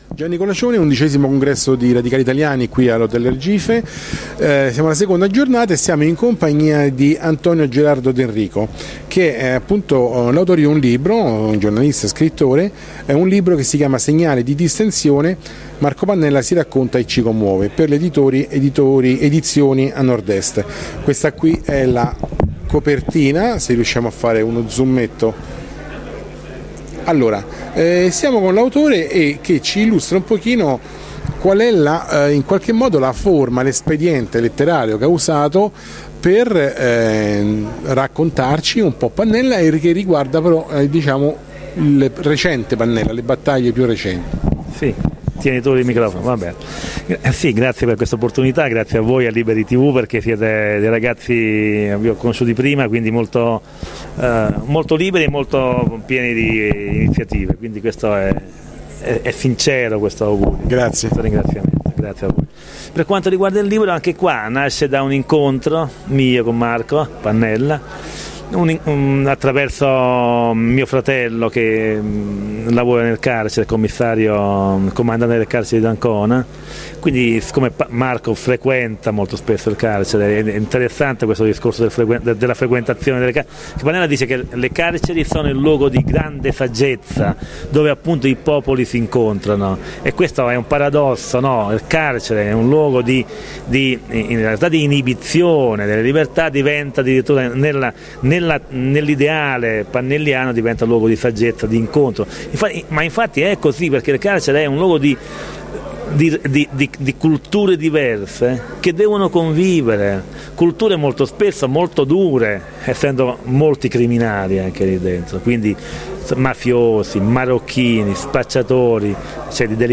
VIDEO | Intervista
Roma XI Congresso di Radicali Italiani - 1-4 Novembre 2012.